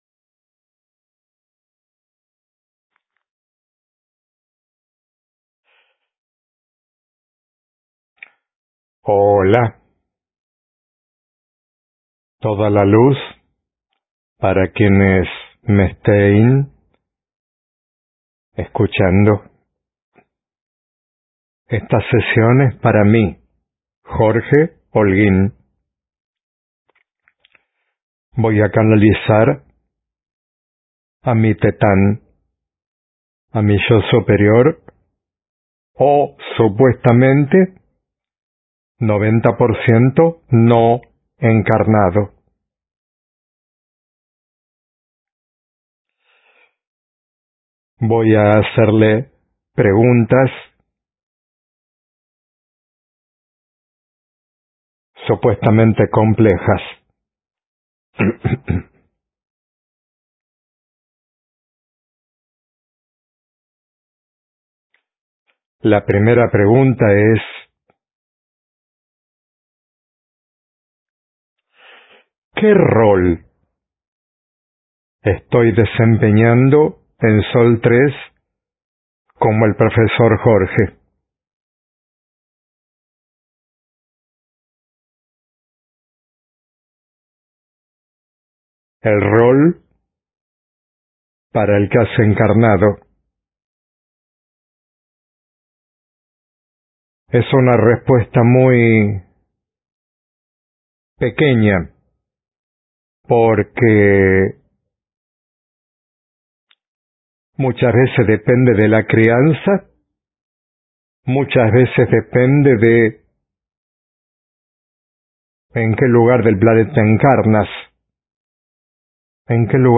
Sesión del 17/12/2025 Médium